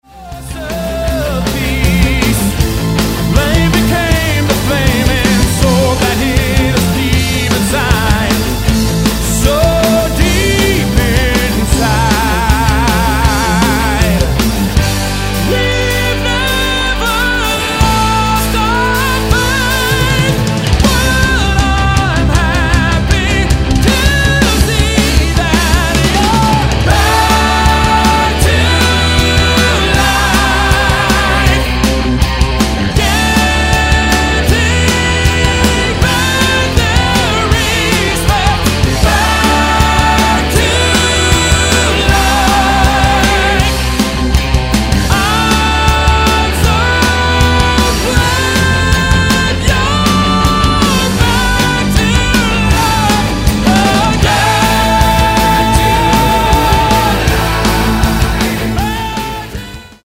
(low quality)